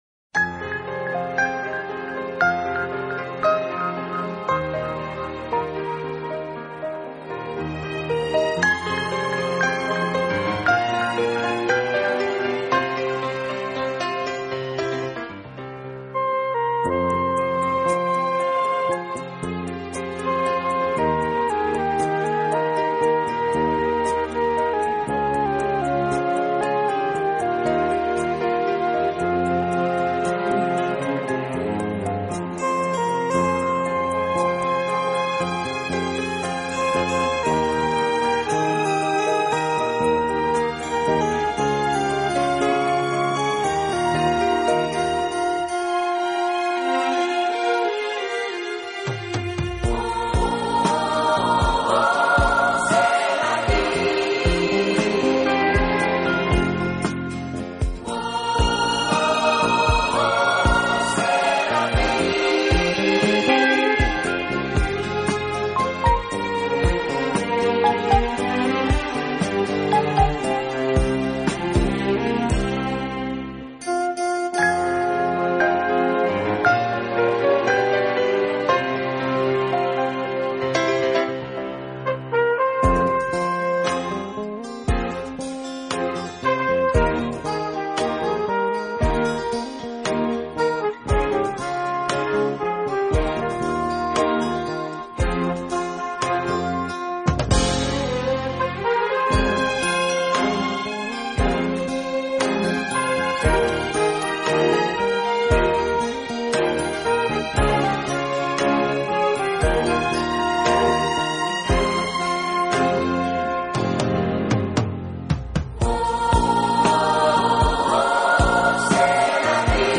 【顶级轻音乐】
其风格清新明朗，华丽纯朴，从不过分夸张。